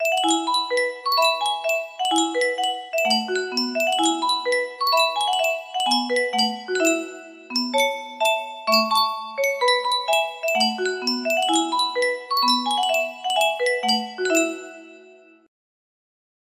Yunsheng Music Box - Oh Susanna 059Y music box melody
Full range 60